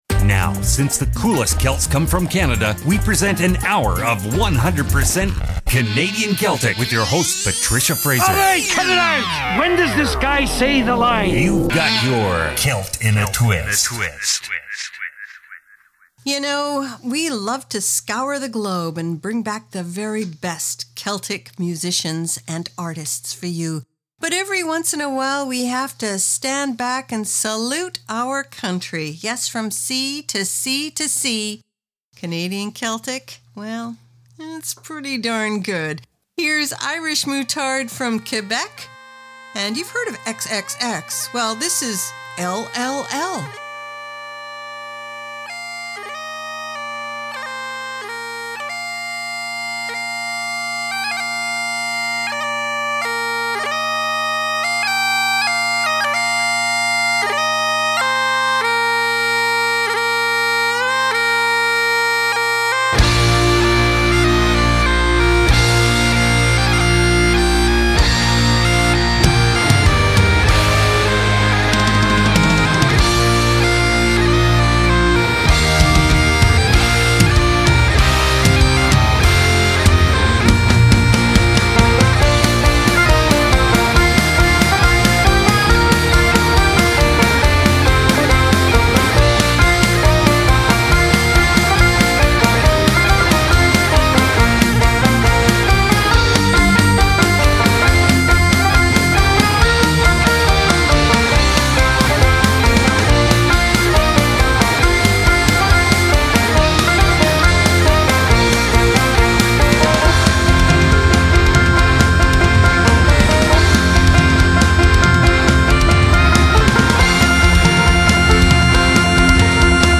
Canada's Contemporary Celtic Radio Hour
An hour of 100% Canadian Celtic